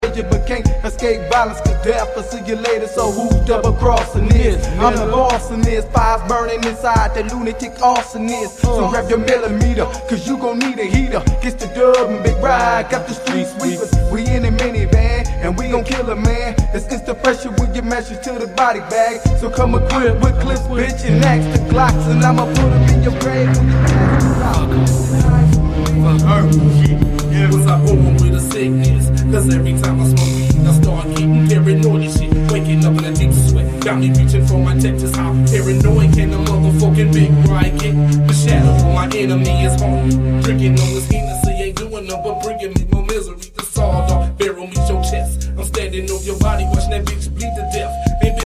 gangsta rap